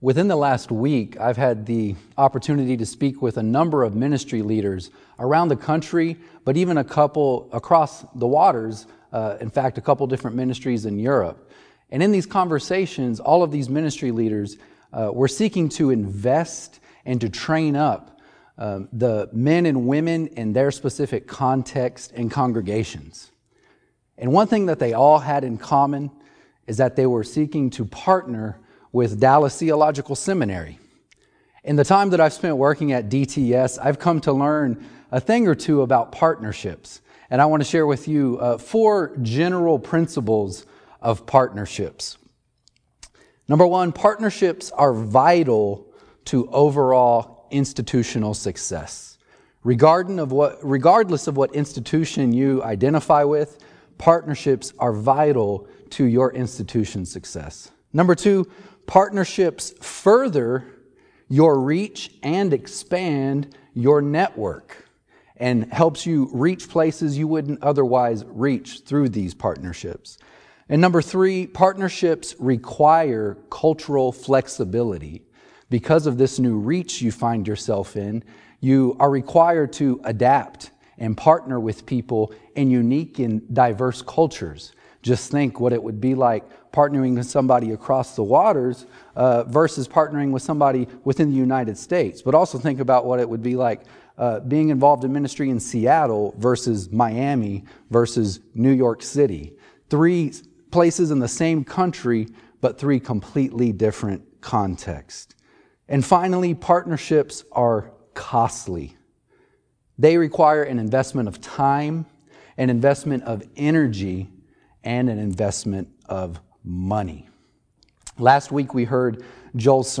6-28-20SermonAudio.mp3